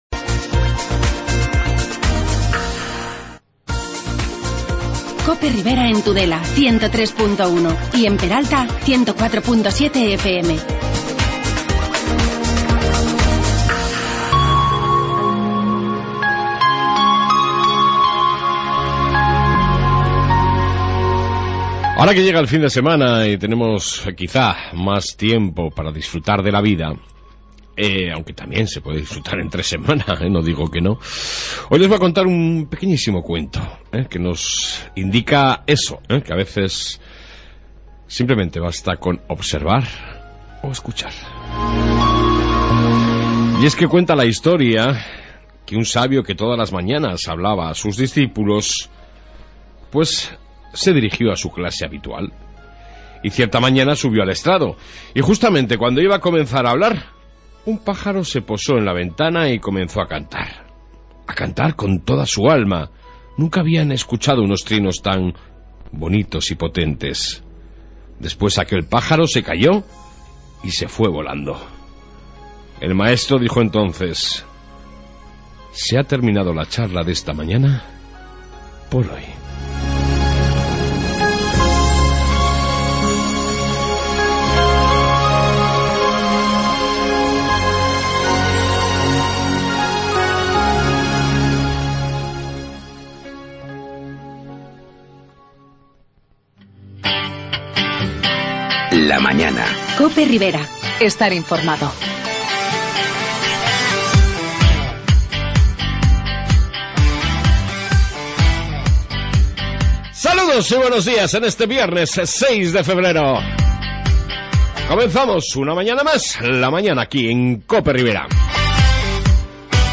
Noticias y entrevista